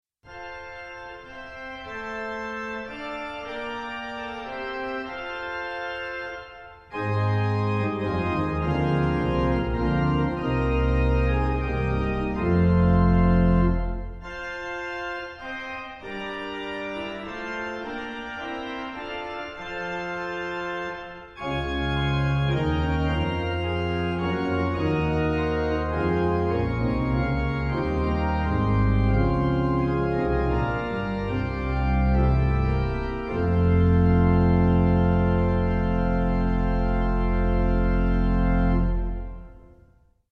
organ of St Ann's Church